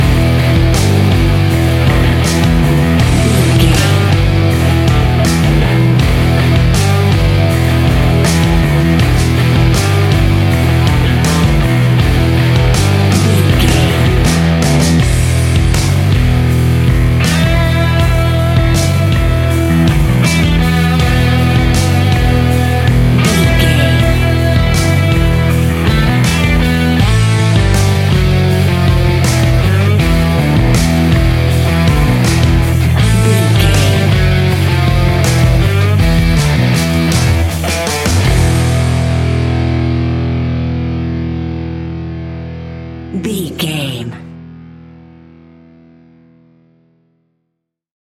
Epic / Action
Aeolian/Minor
hard rock
heavy metal
blues rock
distortion
rock instrumentals
rock guitars
Rock Bass
heavy drums
distorted guitars
hammond organ